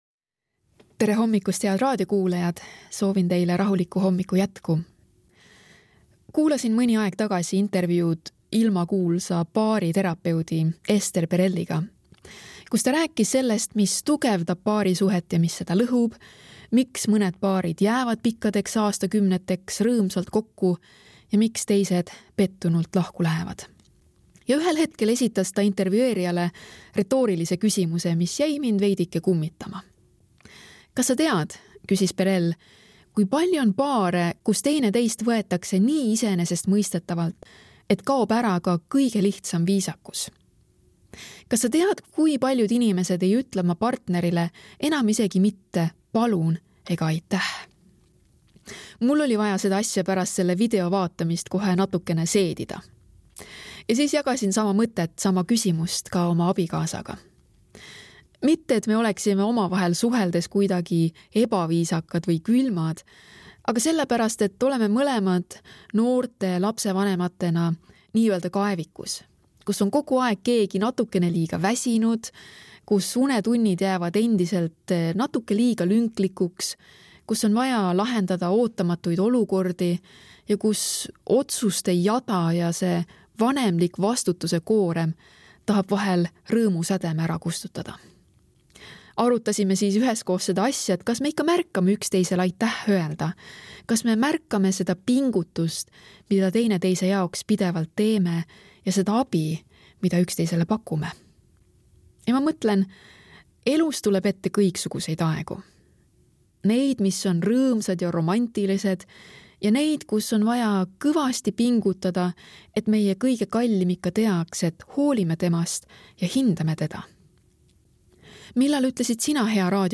hommikumõtisklus ERR-s 13.11.2025
Hommikupalvused